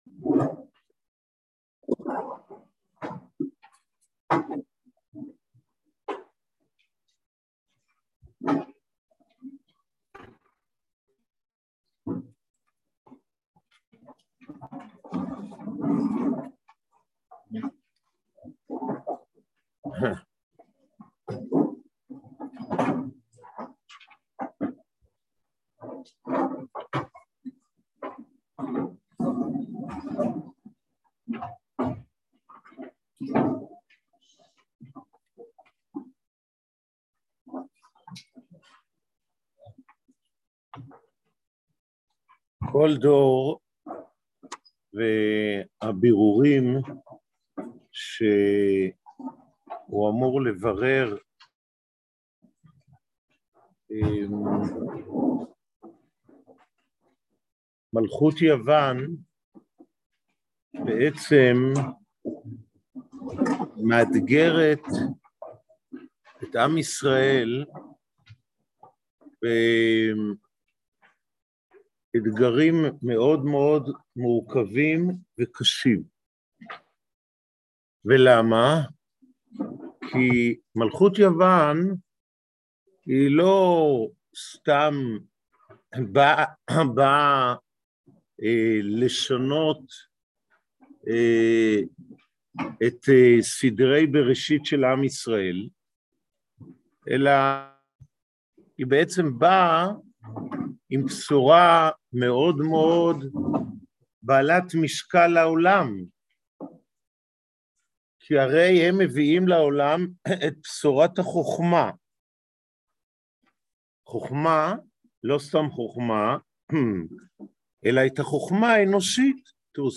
לשם מה הנס היה דווקא במנורה? | יום עיון לחנוכה תשפ"ב | מדרשת בינת